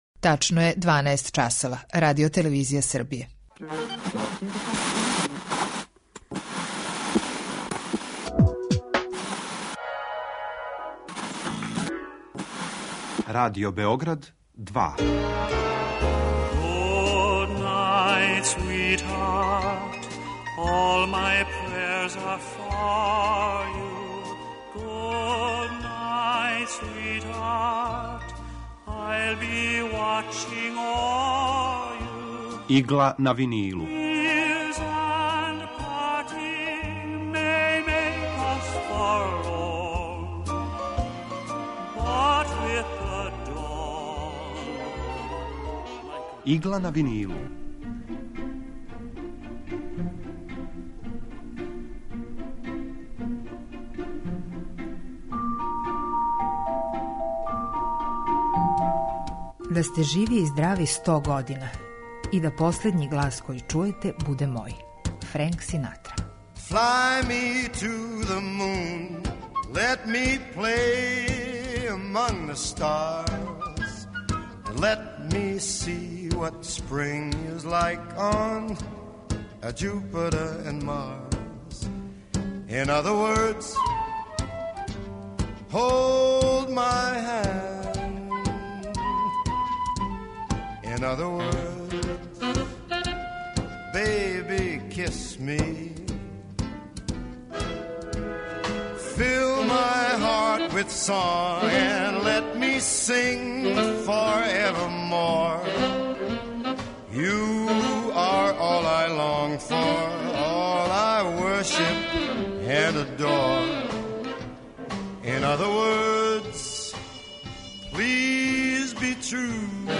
Евергрин музика
У Игли на винилу представљамо одабране композиције евергрин музике од краја 40-их до краја 70-их година 20. века.